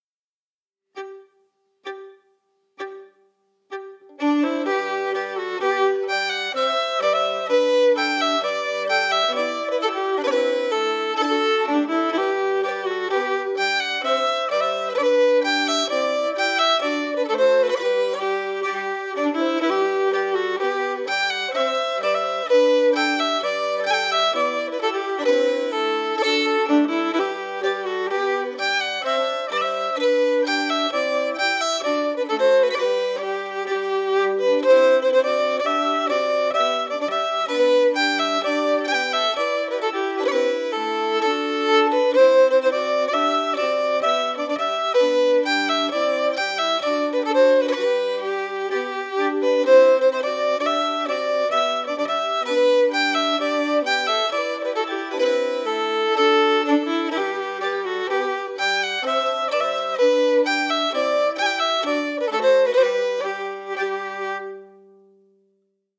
Key: G
Form: March
Melody emphasis
Region: Cape Breton, Canada